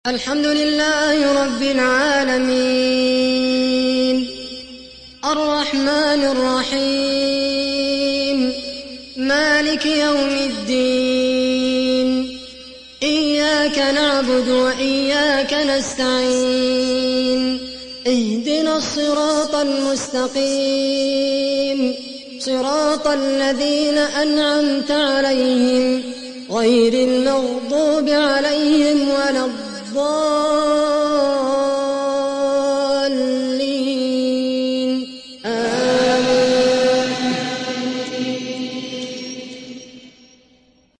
Riwayat Hafs dari Asim